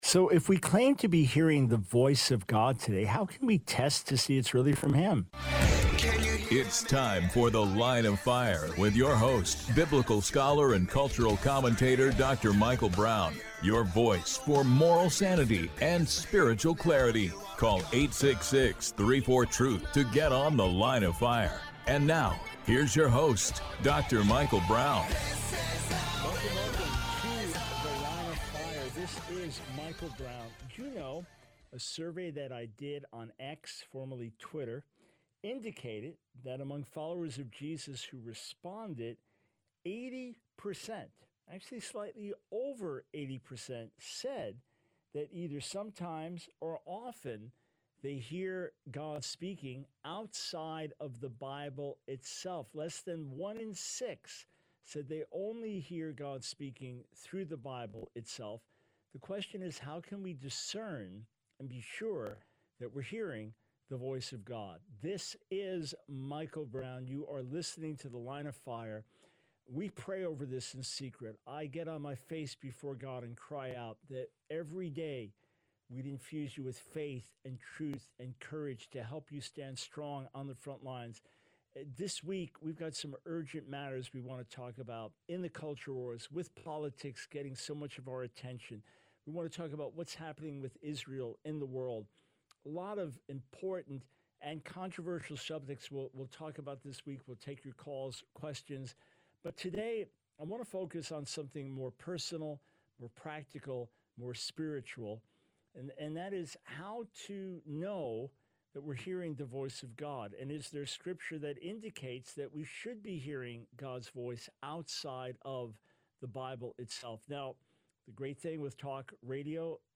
The Line of Fire Radio Broadcast for 09/09/24.